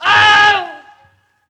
Wilhelm Scream Alternative 4
Category 🗣 Voices
death fall falling famous killed legend legendary male sound effect free sound royalty free Voices